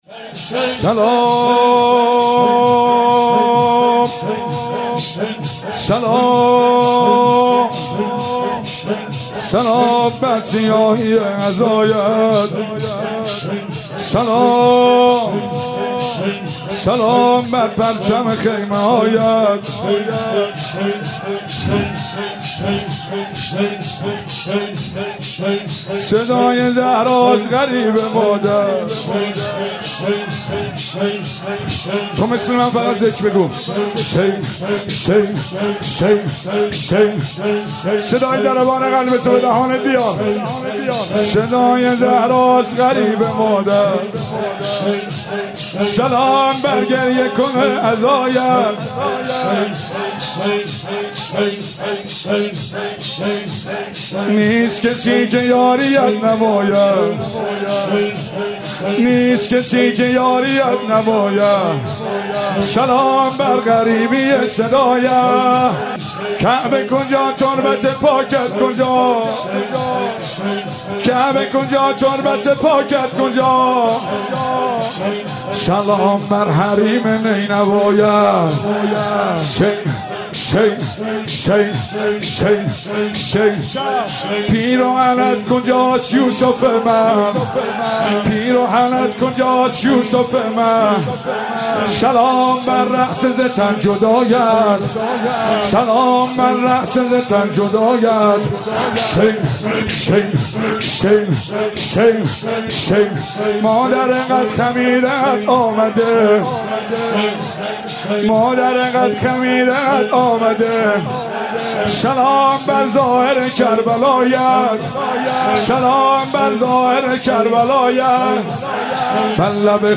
شور و زمزمه